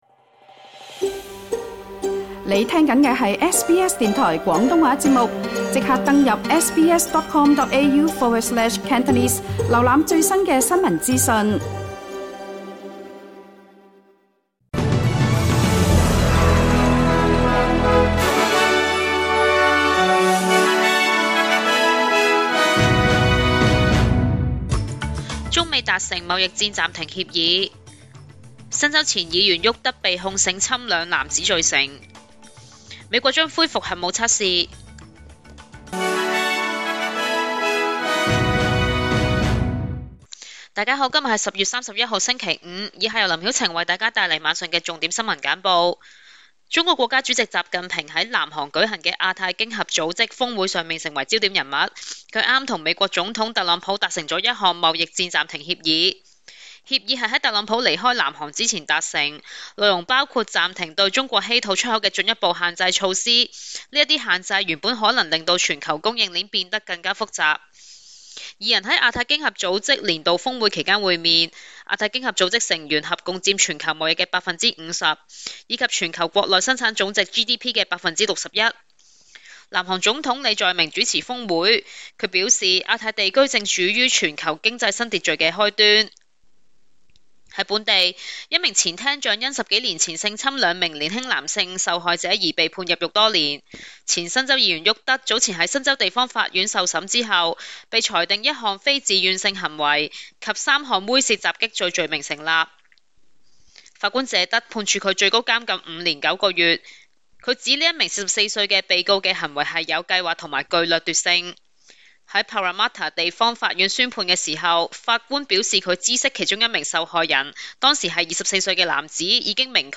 SBS 廣東話晚間新聞